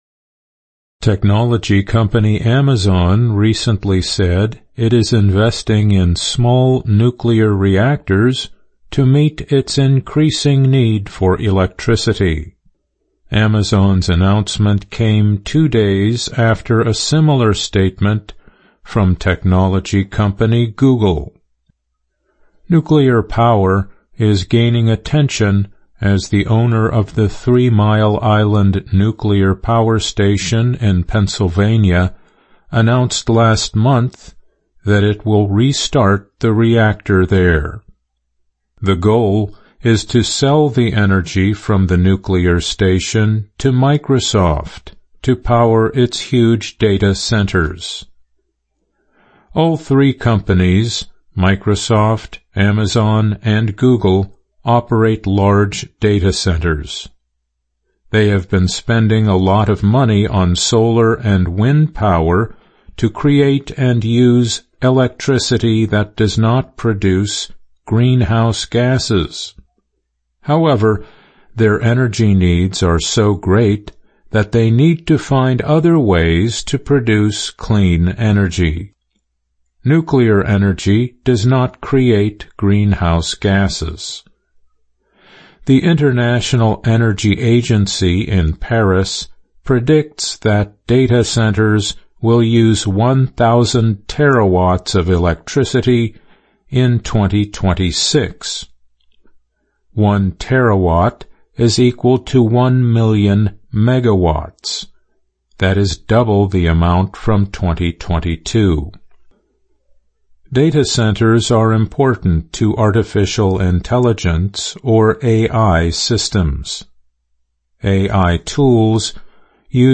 VOA慢速英语逐行复读精听提高英语听力水平